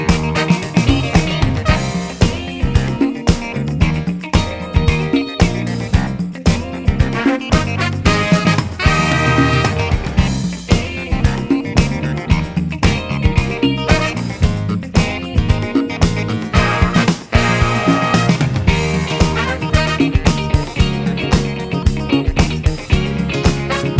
no Backing Vocals Disco 3:35 Buy £1.50